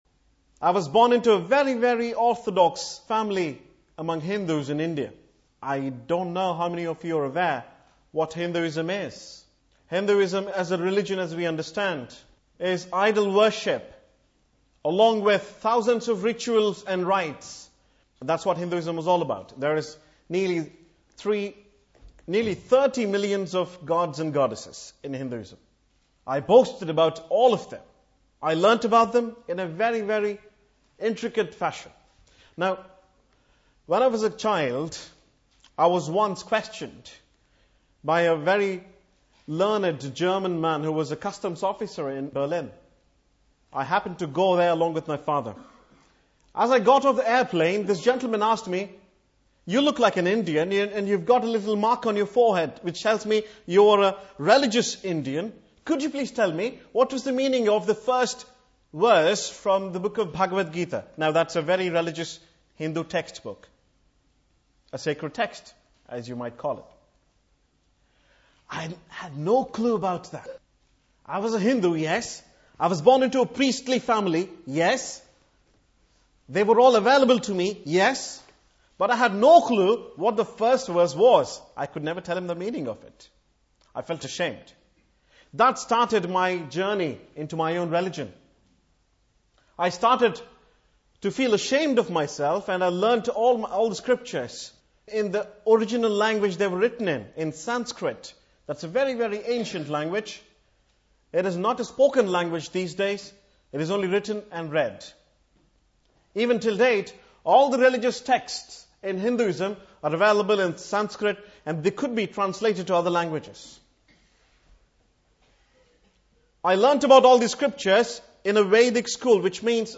Testimonies